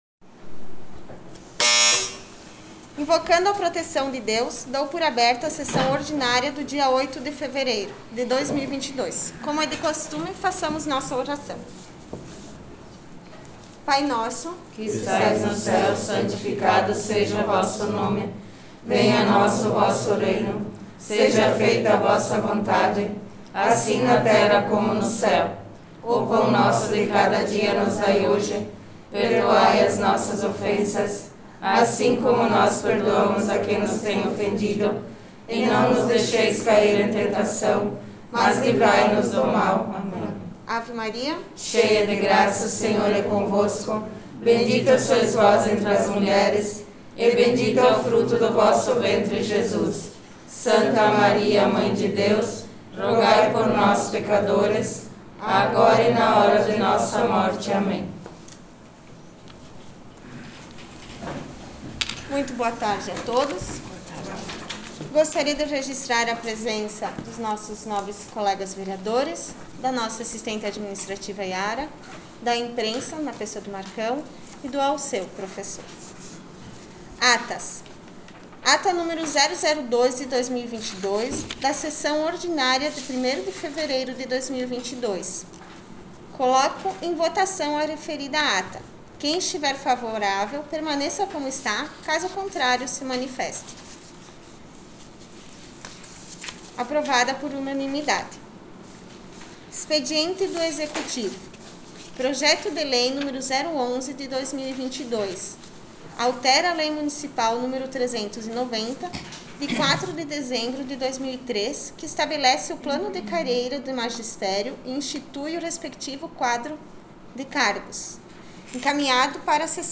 3 - Sessão Ordinária 08-02.m4a